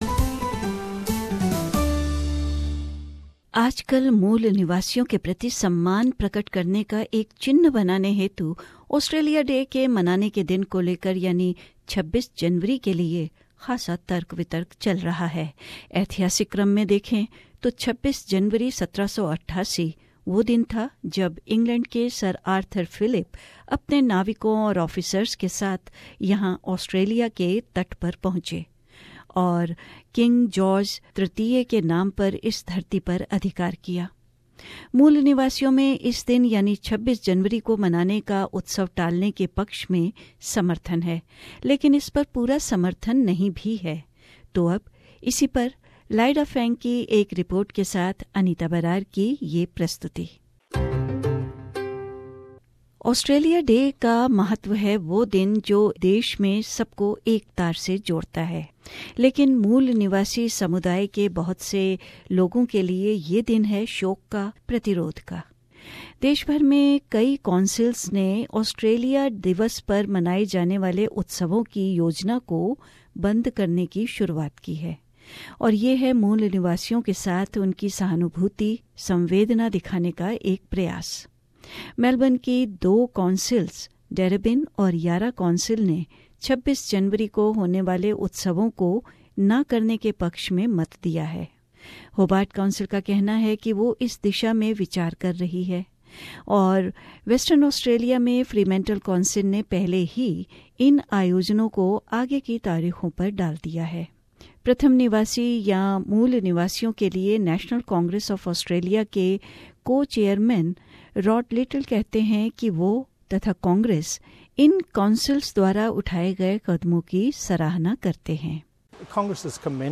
Debate is growing across the country about scrapping Australia Day celebrations on January the 26th as a sign of respect for Indigenous Australians.Among Indigenous people, there is, of course, strong support for moving the celebrations from that date -- but not total support. A feature